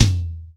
TOM     3A.wav